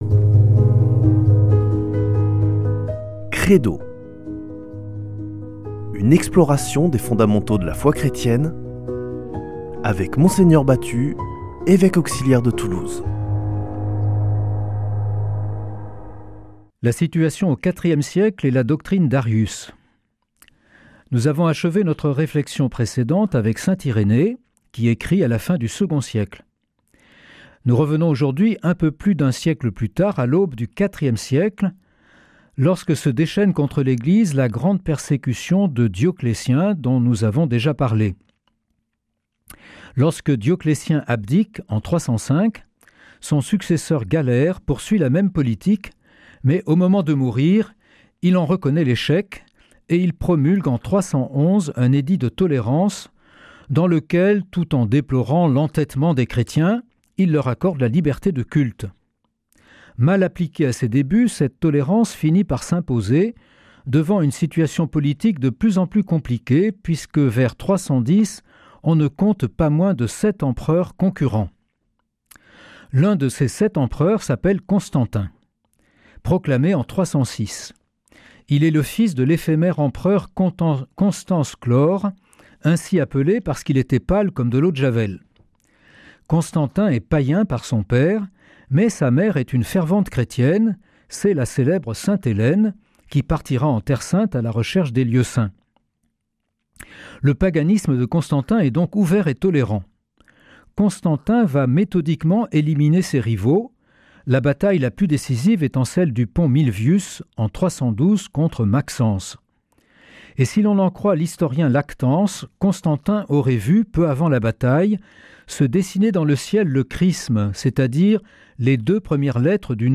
Une émission présentée par
Mgr Jean-Pierre Batut